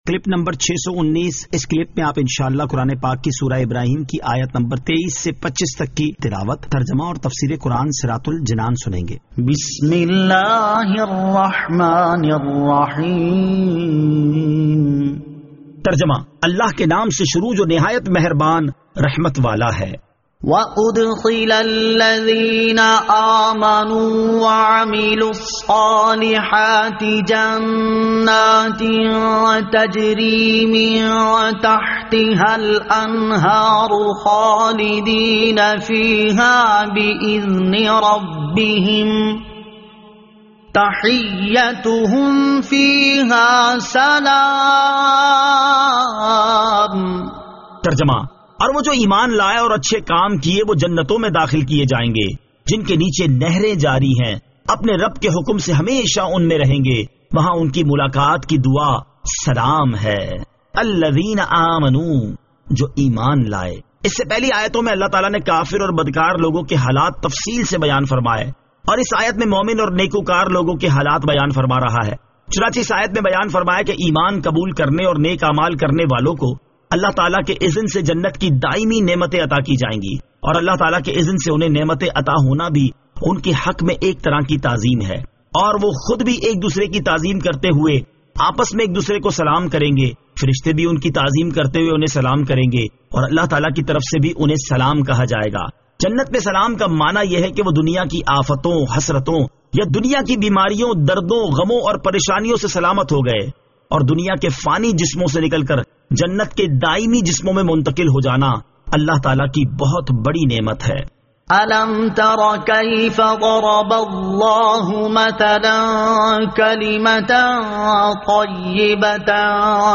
Surah Ibrahim Ayat 23 To 25 Tilawat , Tarjama , Tafseer